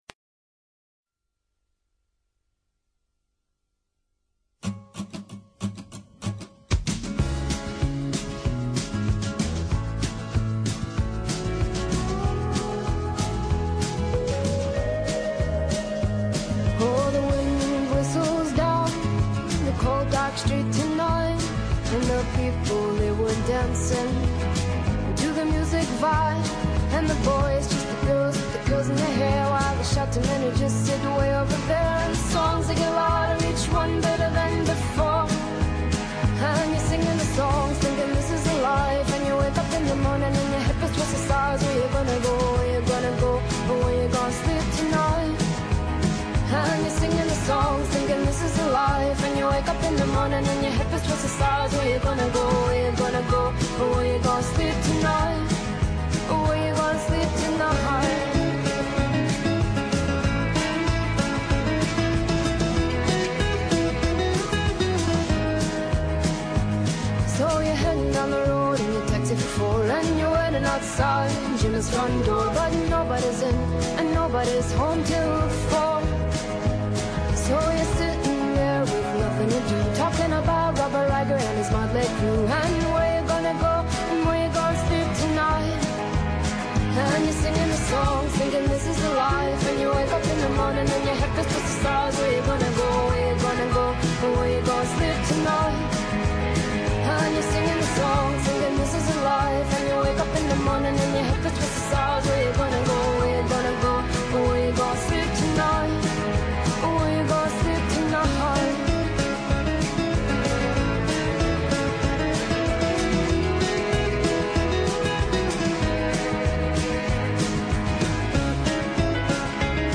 Categories:   Pop